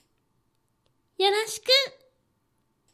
ダウンロード 女性_「さよーならー」
リアクション挨拶高音